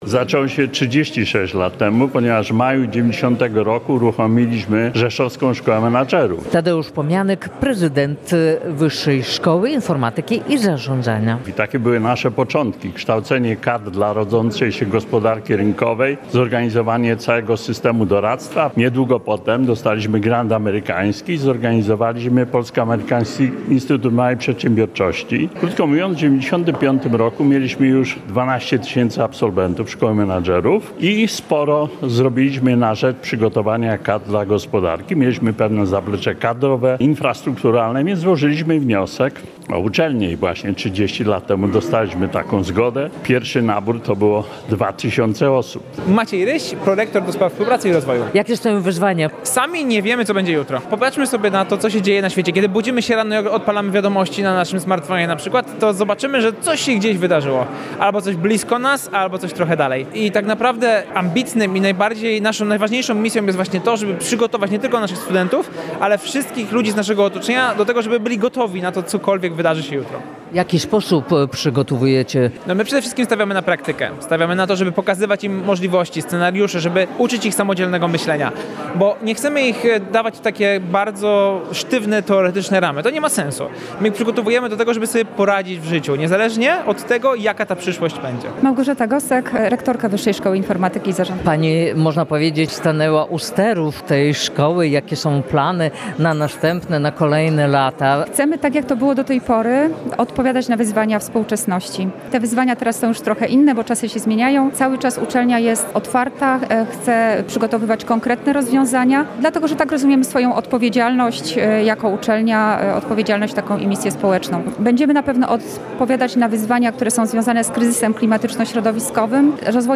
Wyższa Szkoła Informatyki i Zarządzania w Rzeszowie świętuje 30-lecie działalności • Relacje reporterskie • Polskie Radio Rzeszów